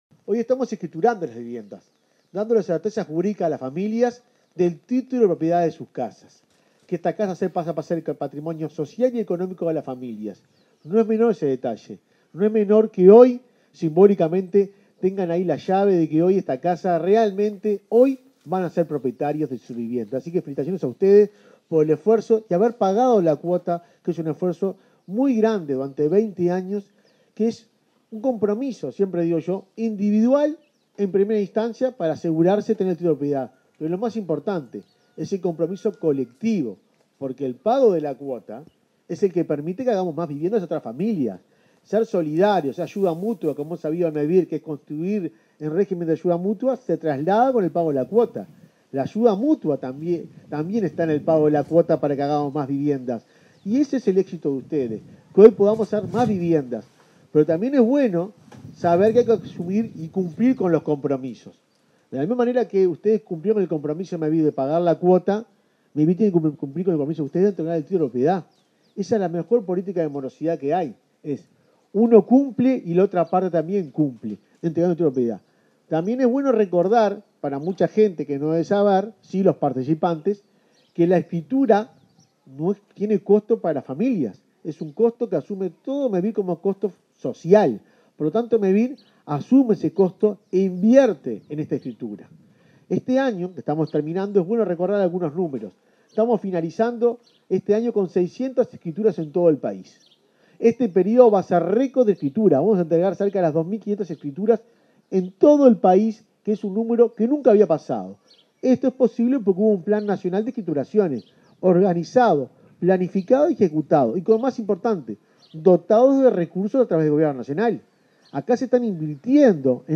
En el marco del acto de escritura de 35 viviendas en Paso Antolín, este 5 de diciembre, disertó el presidente de Mevir, Juan Pablo Delgado.